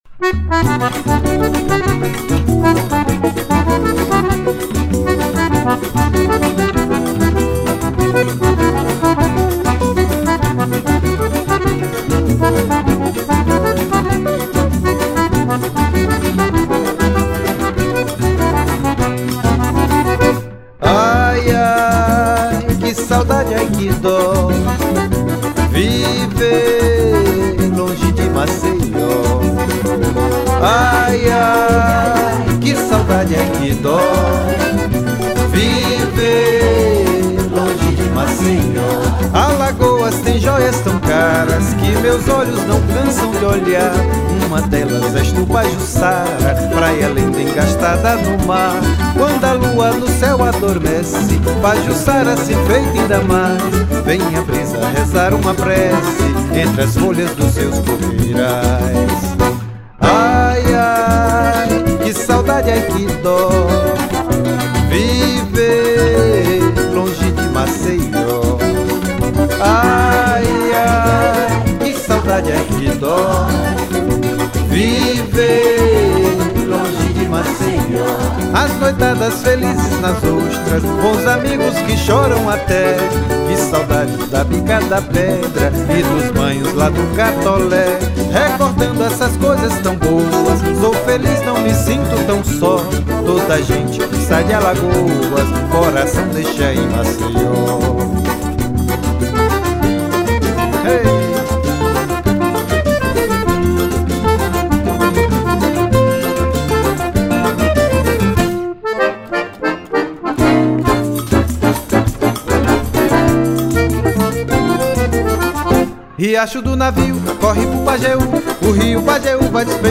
1443   03:16:00   Faixa:     Forró
Acoordeon, Voz
Viola de 12 cordas
Flauta
Bateria
Percussão